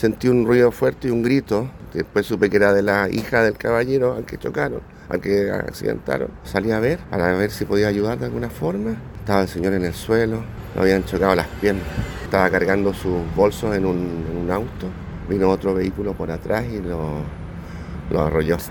En tanto, un testigo agregó que “vino otro vehículo por atrás y lo arrolló”.
cu-testigo-choque-las-condes.mp3